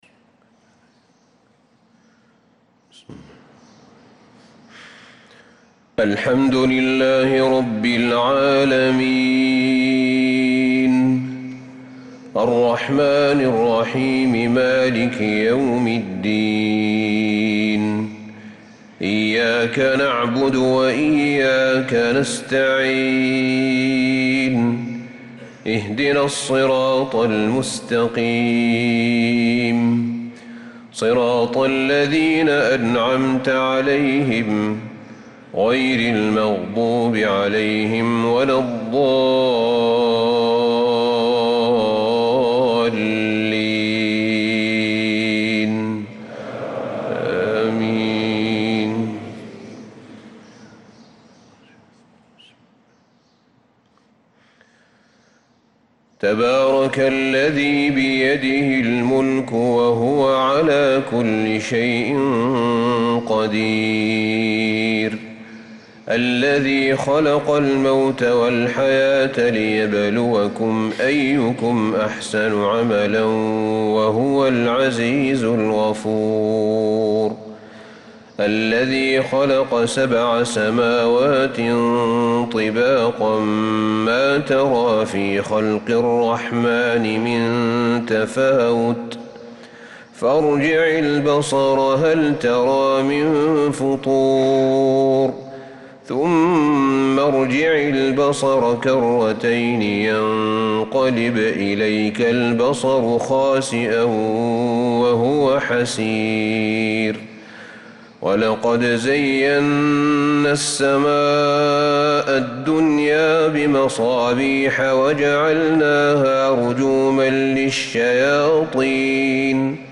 صلاة الفجر للقارئ أحمد بن طالب حميد 1 صفر 1446 هـ